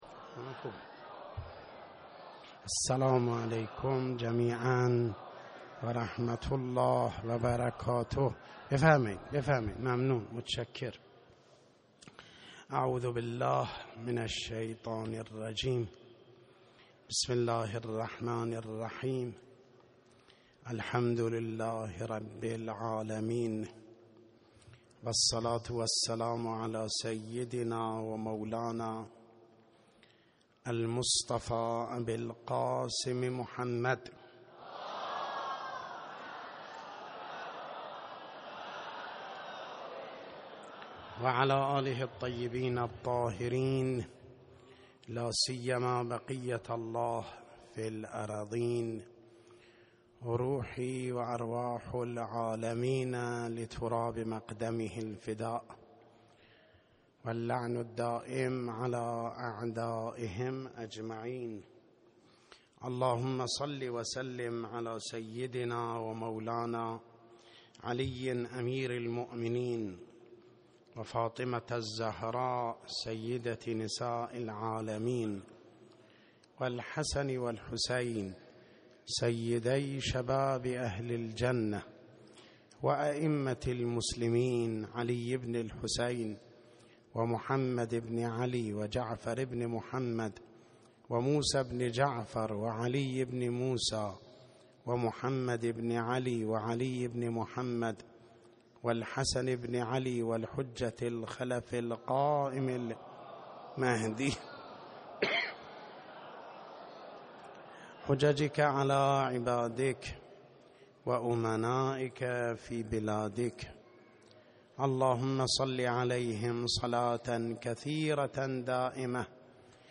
خطبه اول